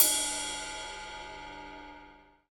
CYM RIDE403R.wav